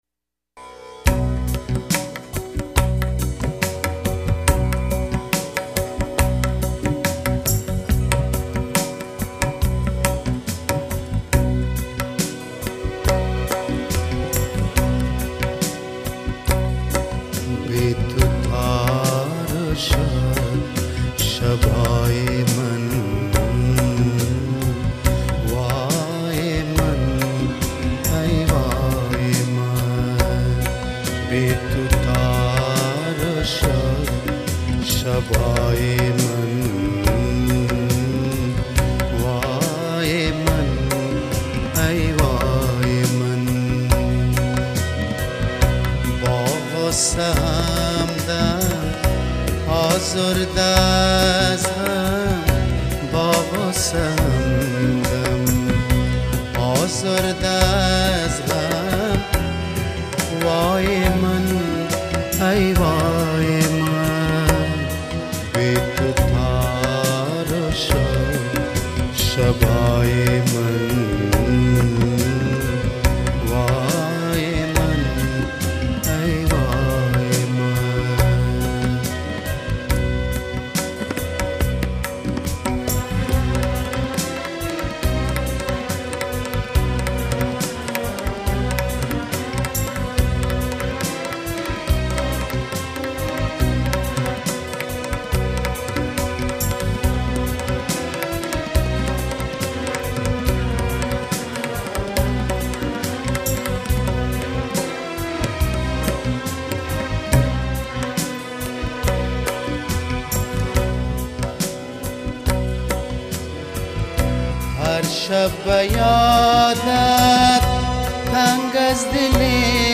хонандаи афғон